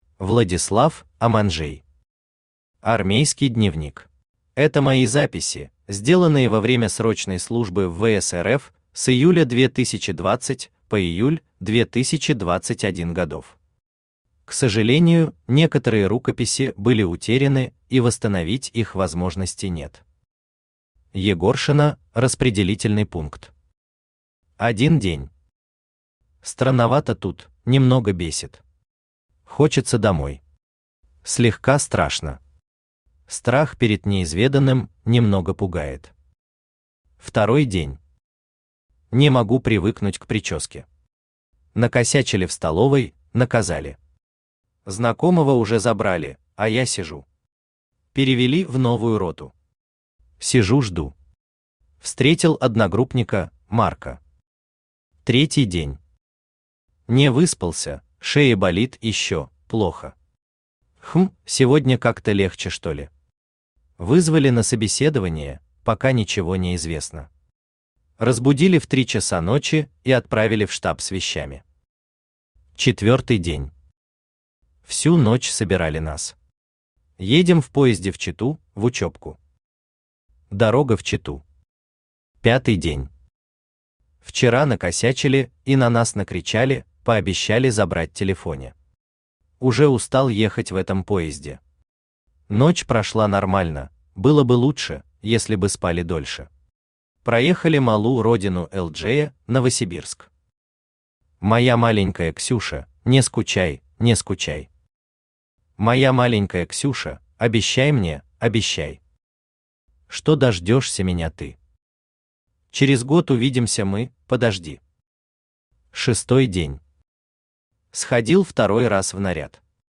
Аудиокнига Армейский дневник | Библиотека аудиокниг
Aудиокнига Армейский дневник Автор Владислав Константинович Аманжей Читает аудиокнигу Авточтец ЛитРес.